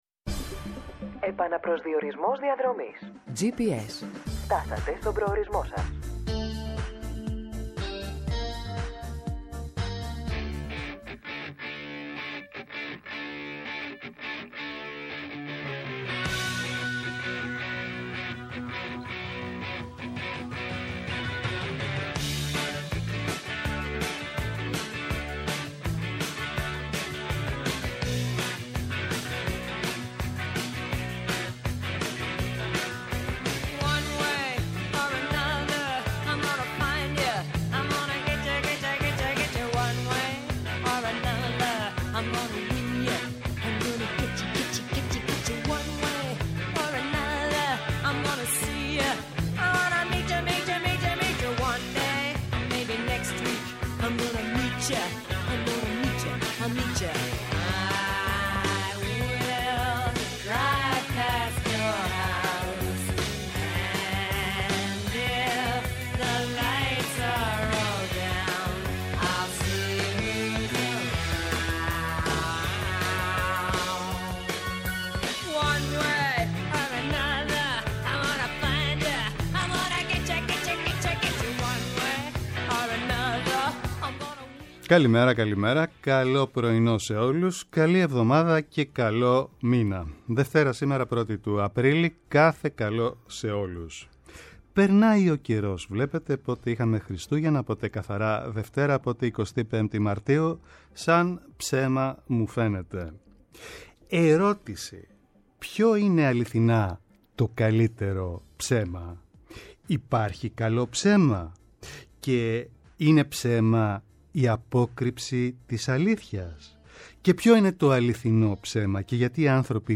-Ο Θανάσης Κοντογεώργης, υφυπουργός παρά τω Πρωθυπουργώ